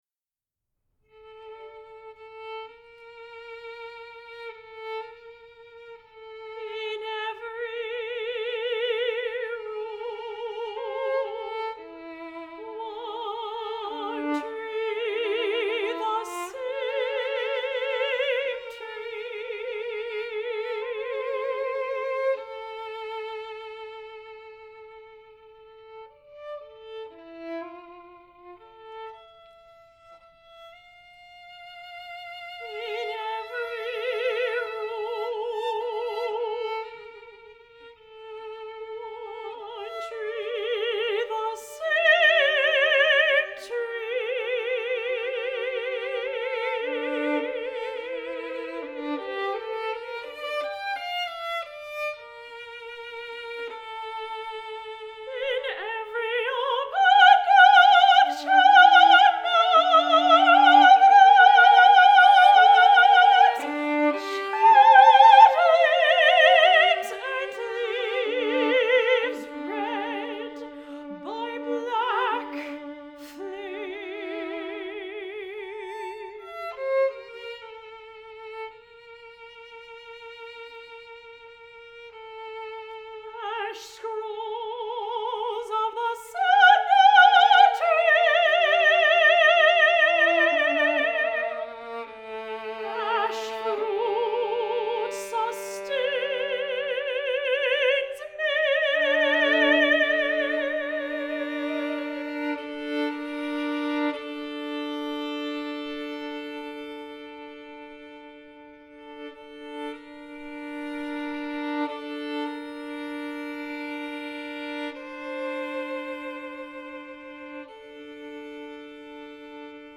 art song